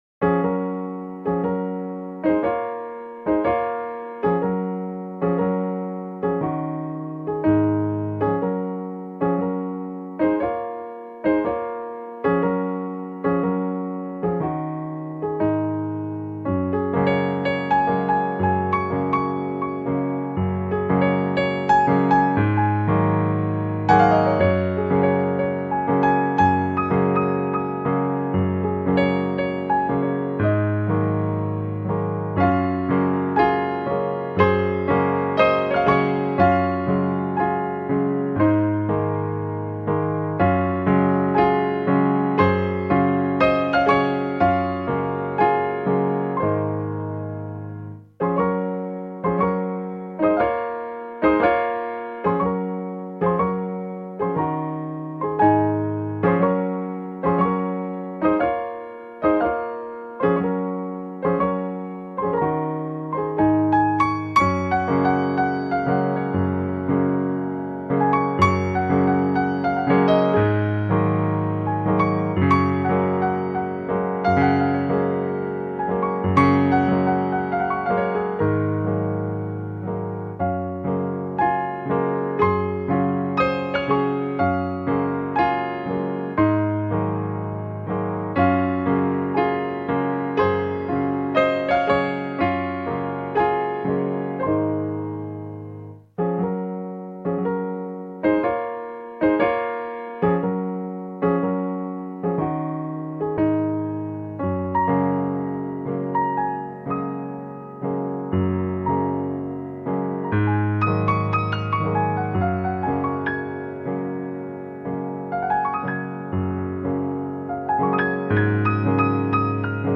A secret garden of lucid musical dreams .
Tagged as: New Age, Instrumental New Age, Contemporary Piano